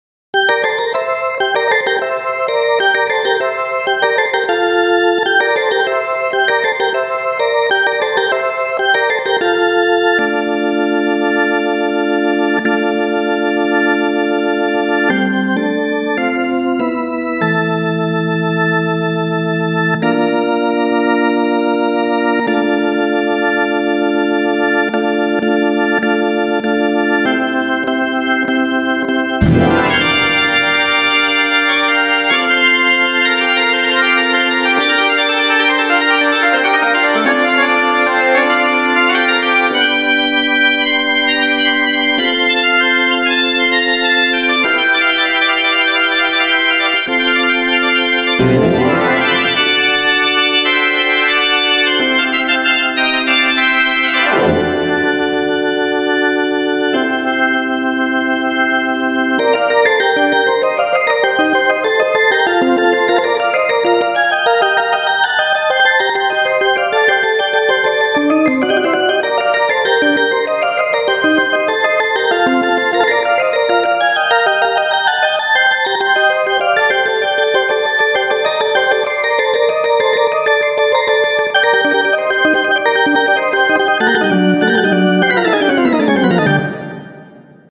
●AllEven●ChurchOrgan